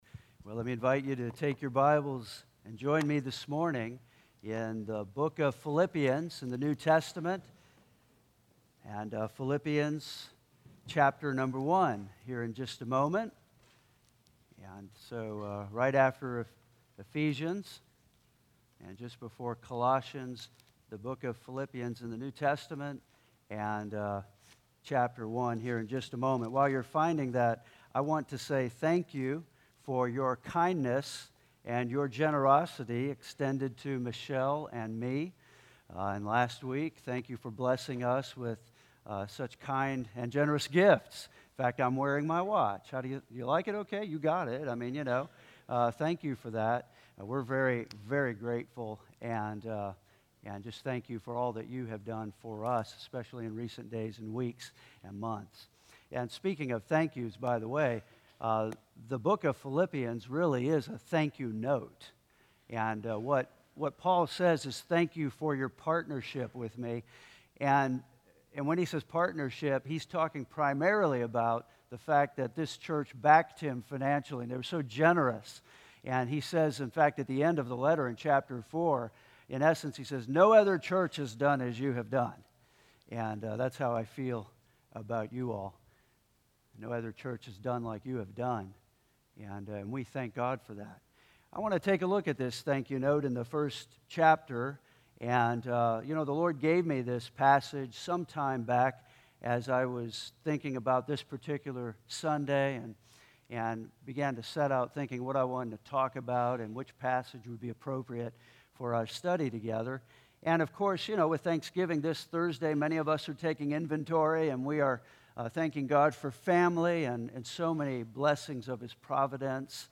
Farewell Sermon